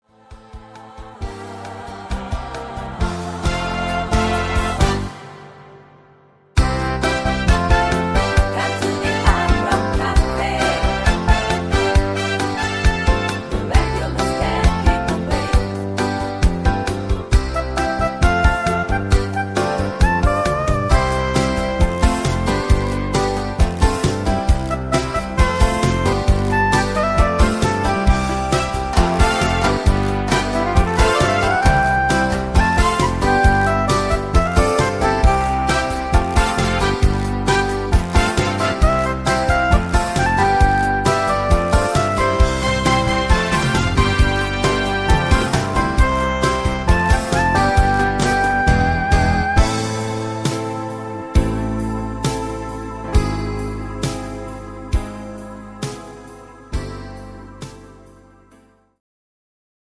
Buy Playback abmischen Buy